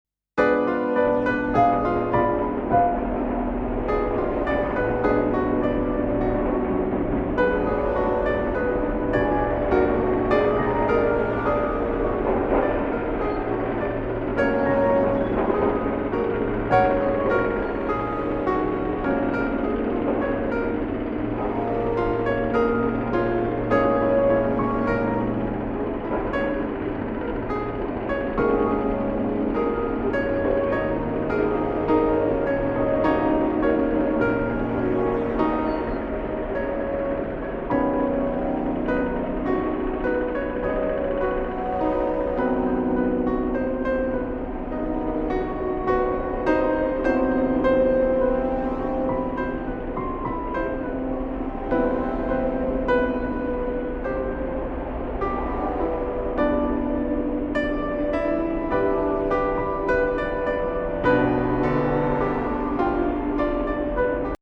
(with background sound)